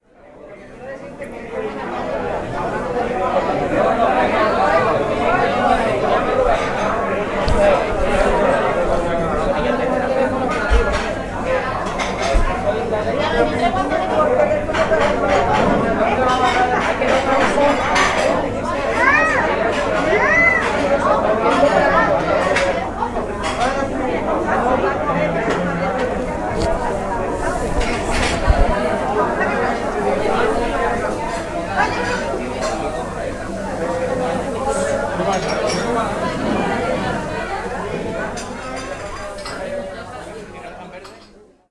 chatter-4.ogg